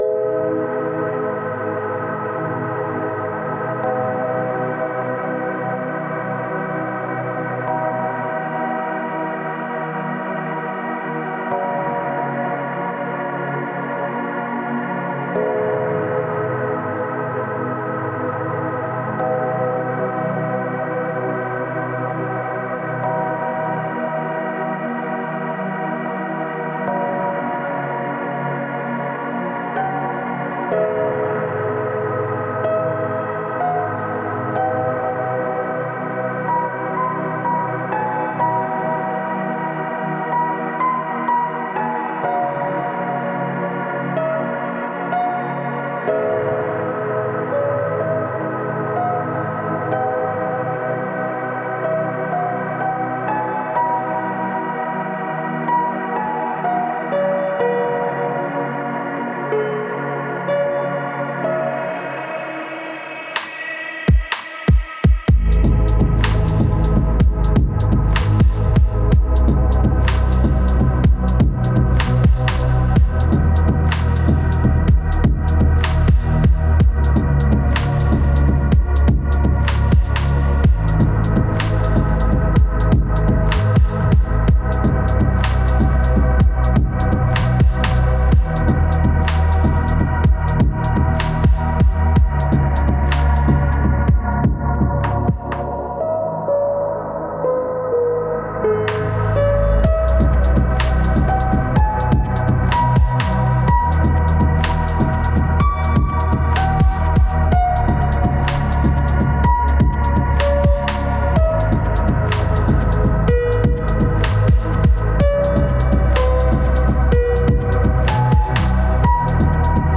это атмосферная композиция в жанре инди-поп